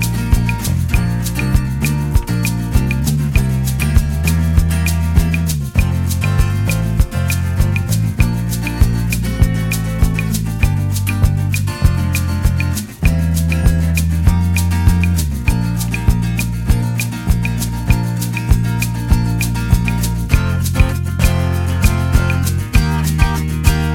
no Piano Pop (1980s) 2:58 Buy £1.50